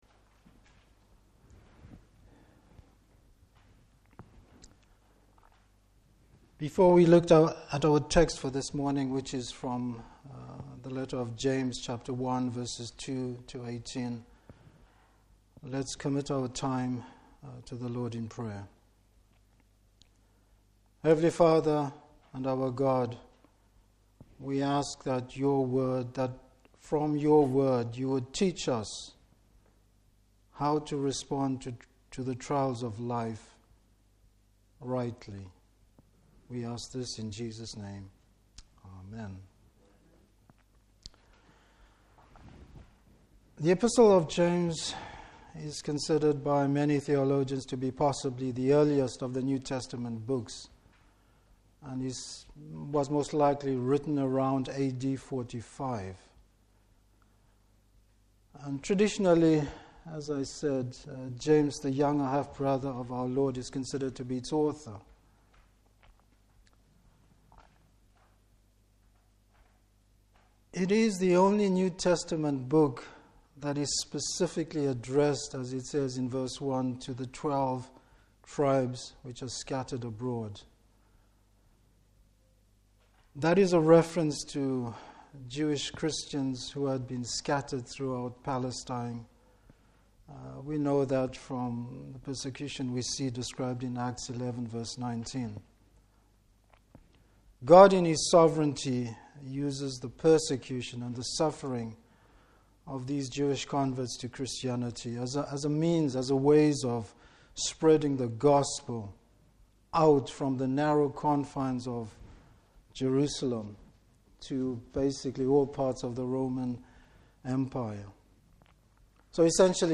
Service Type: Morning Service The benefits of looking to God and making his Word our foundation in difficult times.